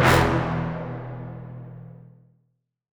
Rare Brass Hit.wav